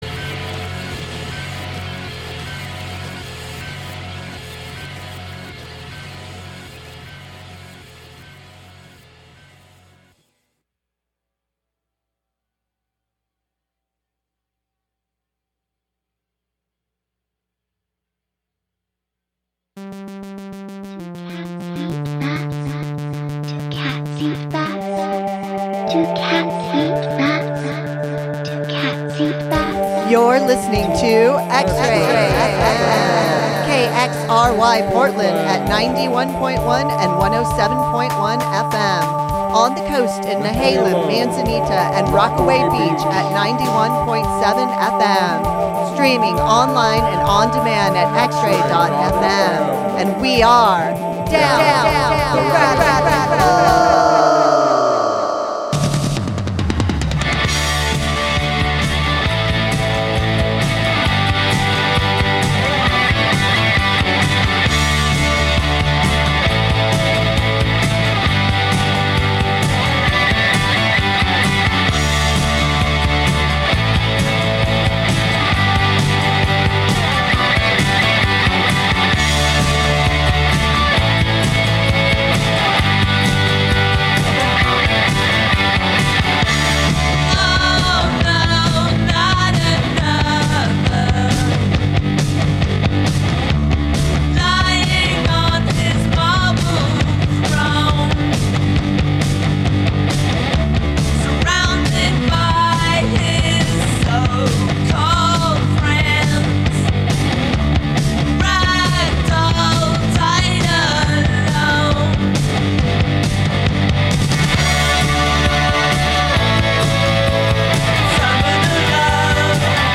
Tune in for dynamite minimal synth,...